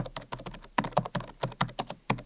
keypad.wav